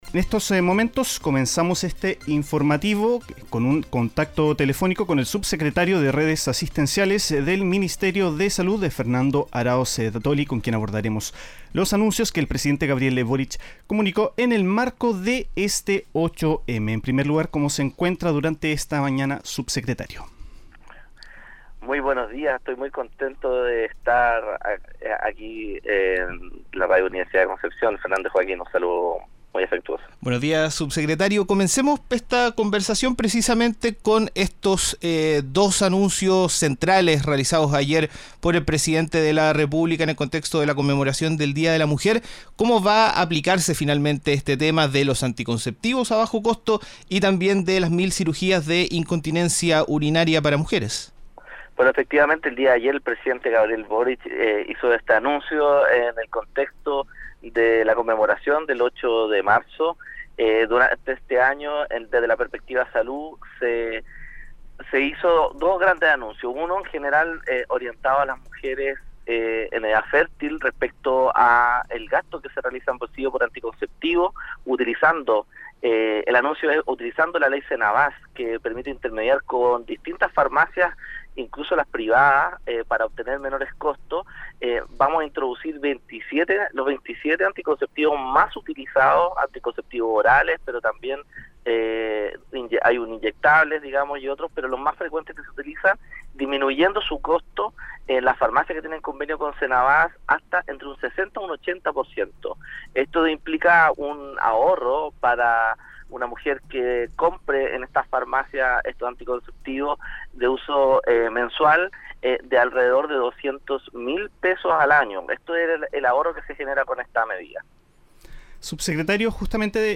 Tras una jornada marcada por las evaluaciones y contingencias relacionadas con el Día Internacional de la Mujer, en Nuestra Pauta AM invitamos al subsecretario de Redes Asistenciales del Ministerio de Salud (Minsal) a entregar más detalles sobre los anuncios del Ejecutivo en materias como equidad de género, derechos sociales y salud. Durante el diálogo, la autoridad política también manifestó su preocupación por el reciente rechazo legislativo a la reforma tributaria, sobre todo, por los perjuicios que podría traer para el proceso de universalización de la atención primaria.